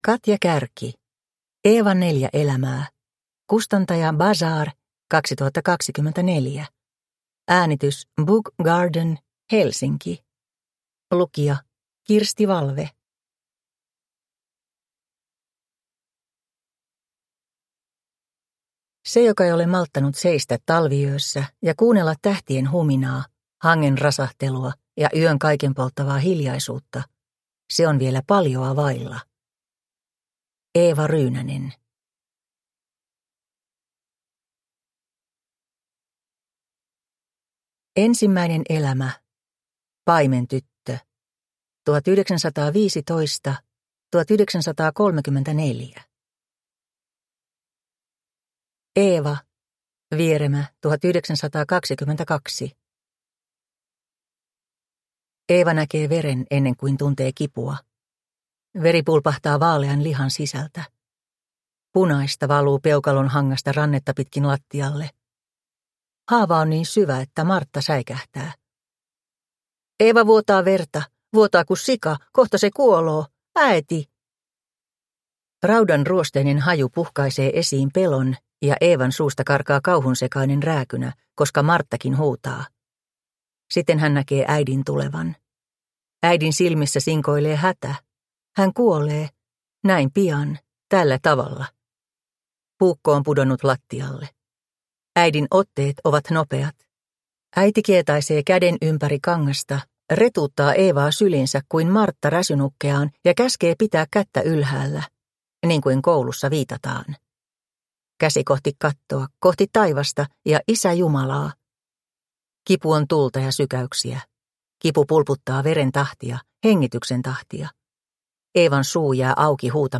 Downloadable Audiobook
Narrator